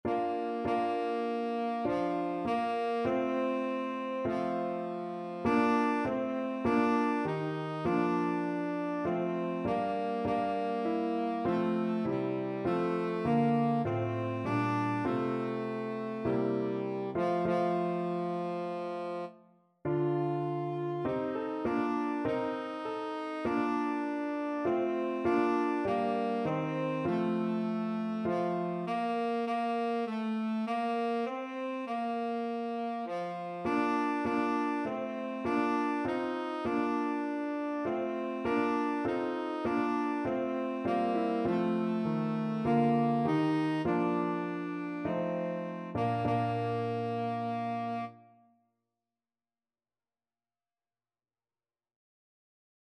Christmas carol
4/4 (View more 4/4 Music)
F4-F5